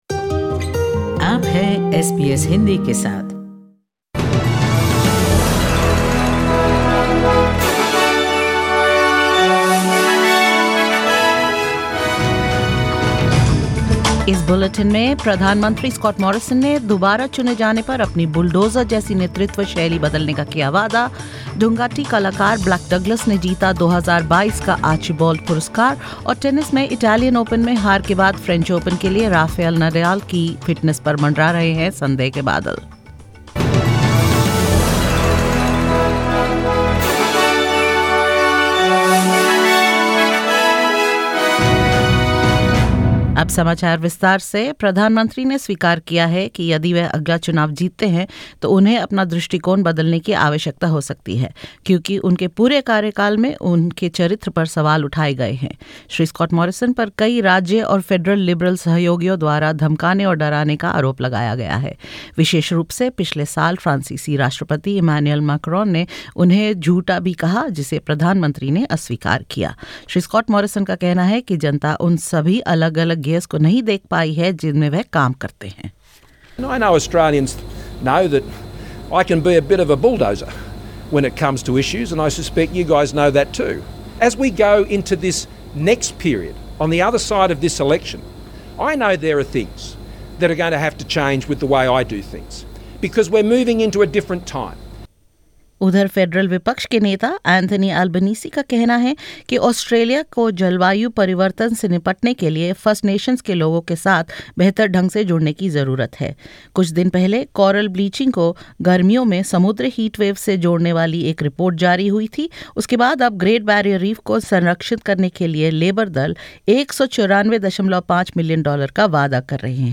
In this latest SBS Hindi bulletin: Prime Minister Scott Morrison promises to change his "bulldozer" style leadership if re-elected; Foreign Minister Marise Payne and Shadow Foreign Minister Penny Wong highlight increasing instability in the Indo-Pacific region in their debate at the National Press Club; Blak Douglas becomes the second Aboriginal artist to claim the Archibald Prize in the competition's 101-year history and more.